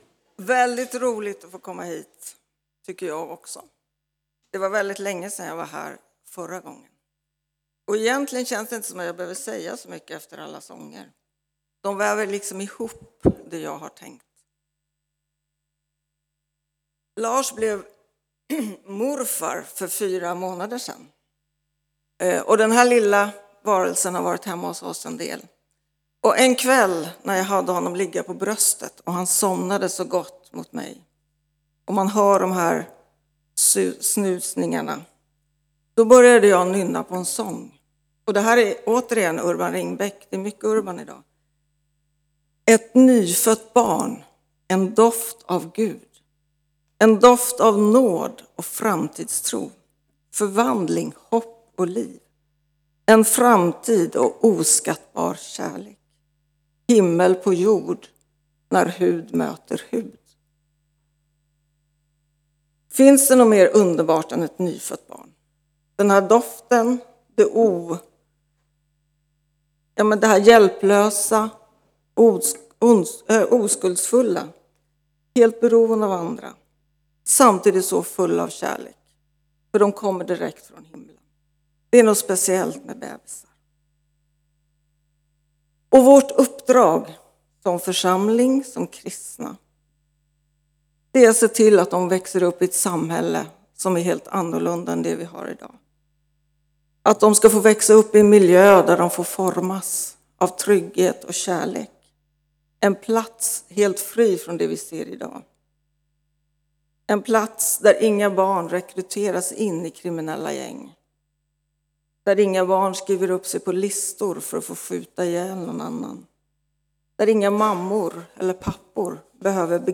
Furuhöjdskyrkan, Alunda